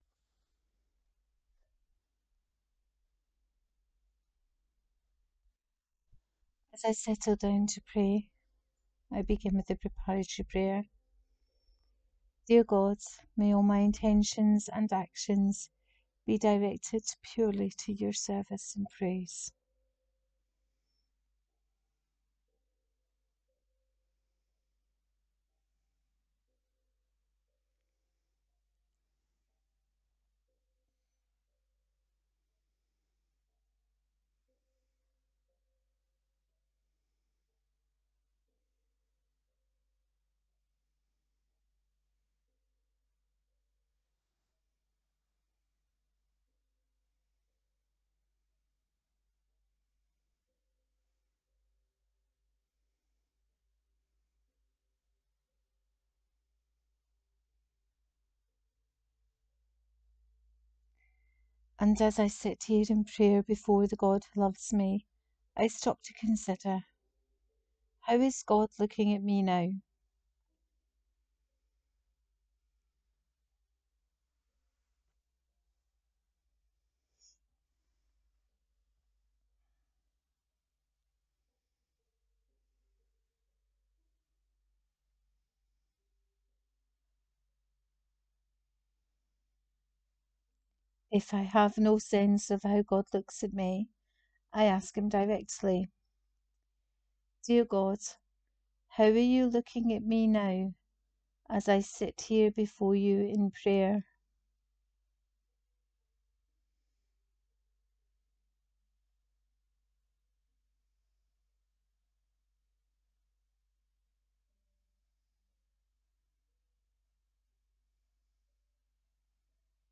40 Day Journey with Julian of Norwich: Day 6 guided prayer.